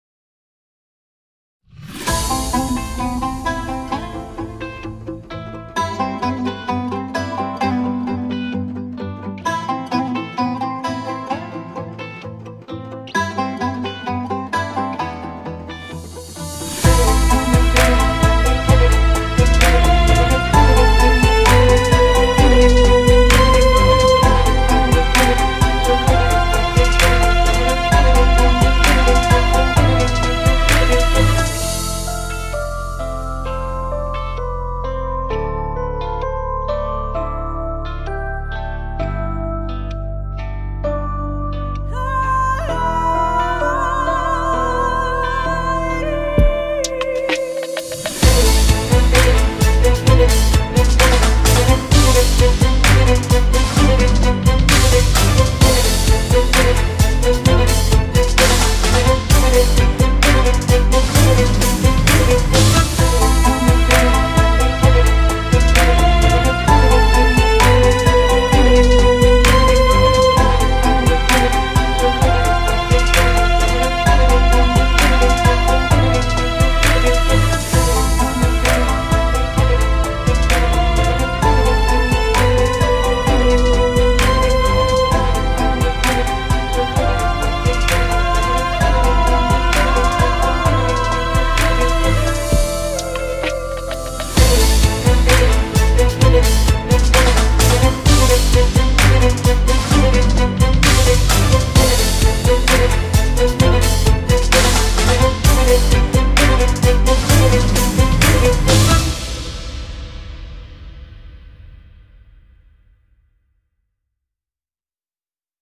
tema dizi müziği